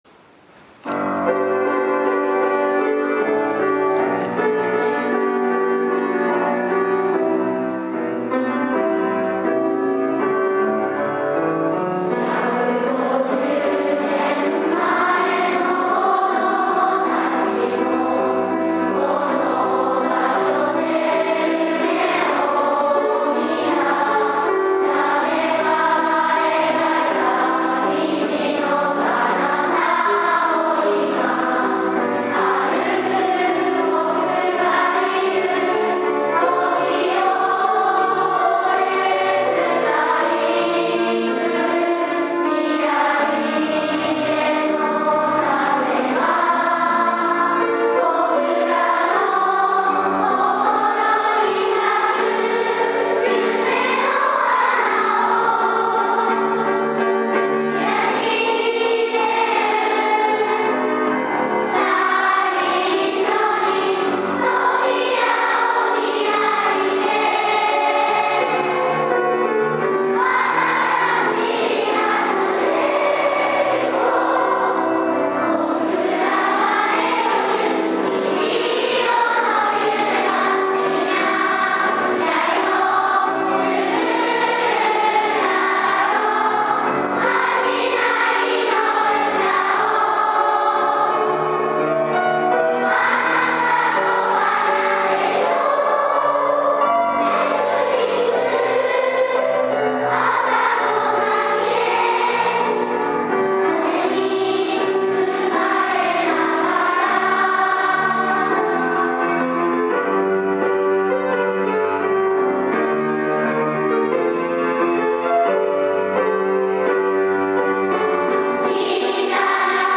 記念式典